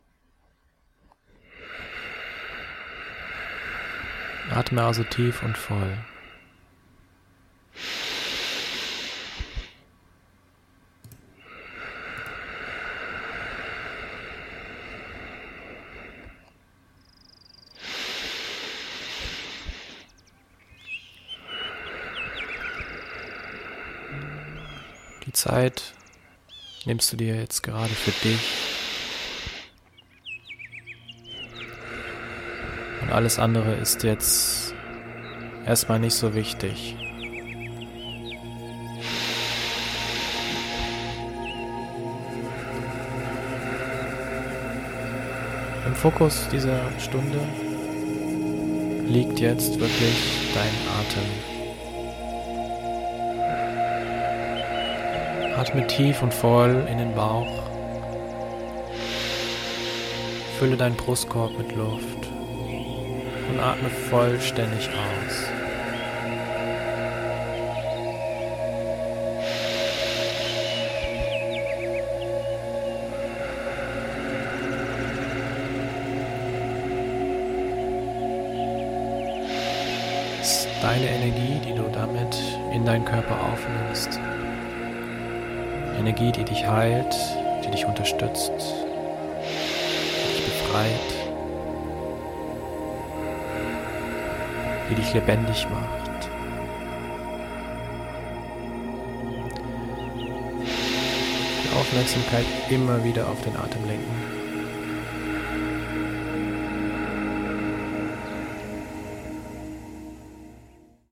Hier ein kleiner Einblick vom Beginn der Atemmeditaton. Die volle Meditation besteht aus verschiedenen Stimmungen, die von 13 Musikstücken untermahlt werden.
In dieser Atemmeditation leite ich dich eine Stunde lang durch das bewusste, volle Atmen an, um die Prozesse bei dir anzustoßen, die gerade dran sind.
Begleitet wird diese Atemmeditation von ausgewählter Musik, die den Weg zu den eigenen Gefühlen erleichtert und diese Stunde des Atmens zu einem wahren Erlebnis machen kann, wenn man sich darauf einlässt.